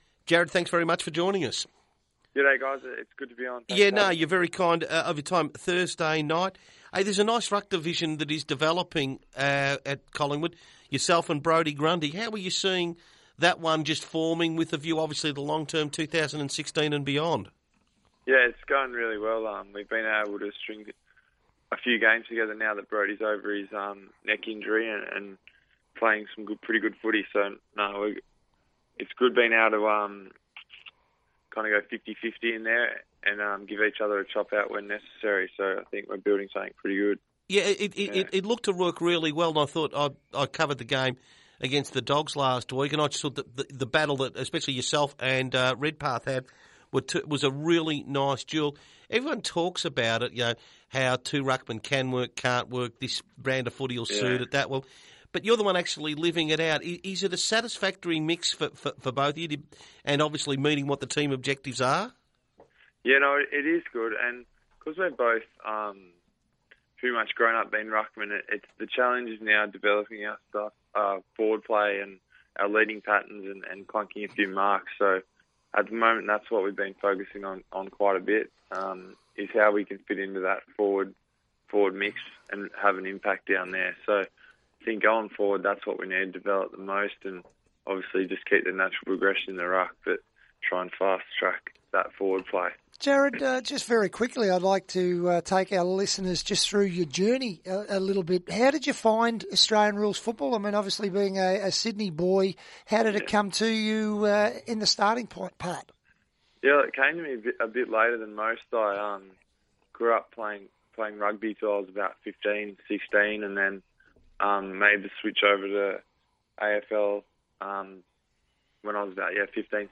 Listen to ruckman Jarrod Witts speak with the SportsDay Radio team on Thursday 30 July 2015.